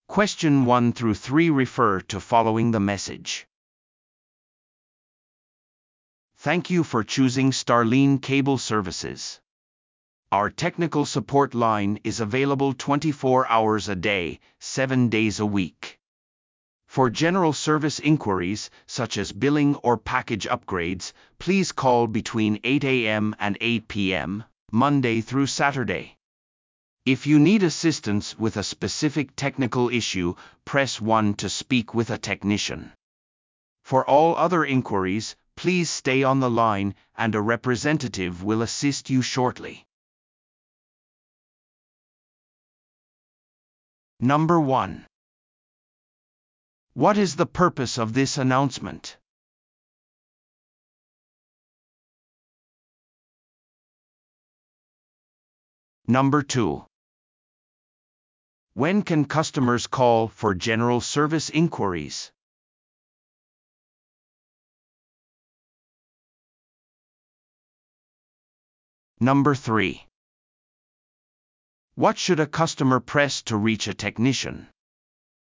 PART４は一人語りの英語音声が流れ、それを聞き取り問題用紙に書かれている設問に回答する形式のリスニング問題。